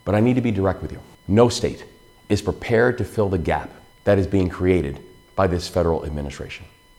Governor Wes Moore delivered a special address to the people of Maryland in the wake of the federal government shutdown.